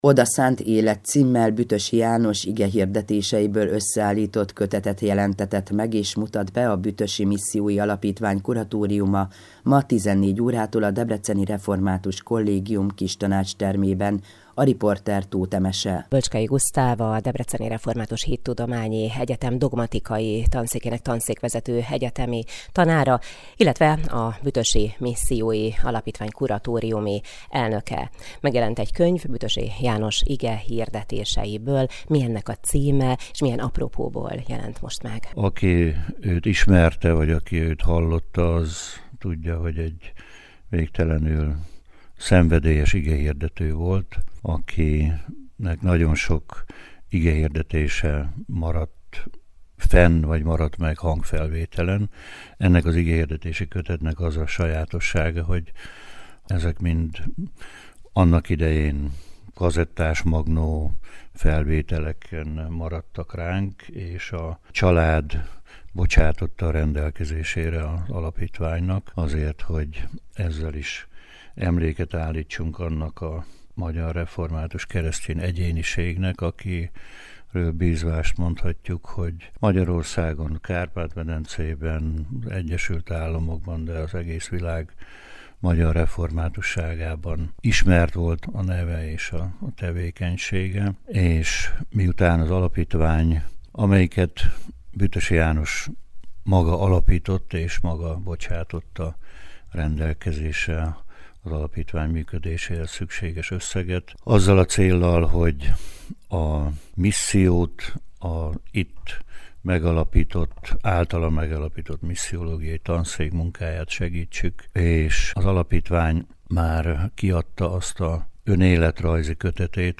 Könyvbemutató a Debreceni Református Hittudományi Egyetemen